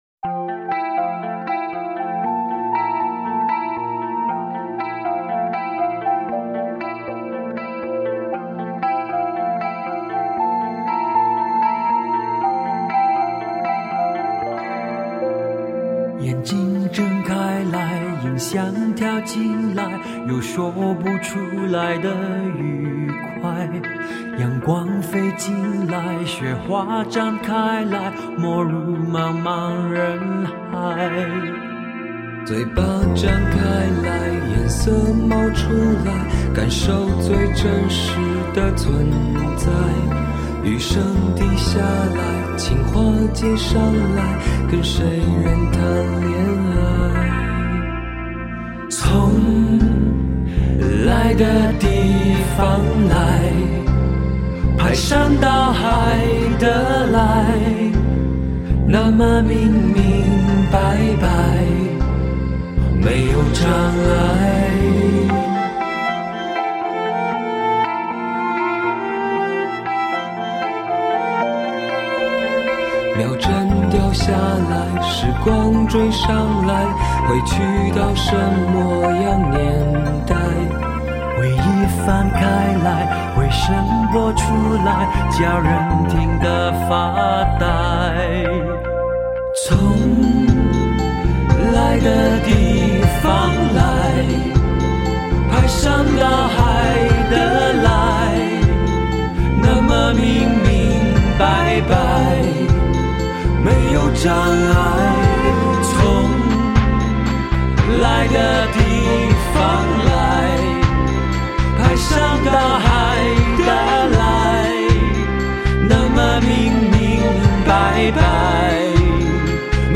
恍惚迷离凄美的电子曲风，直入云霄的中性抚媚唱腔，深具电影质感的凝重气氛。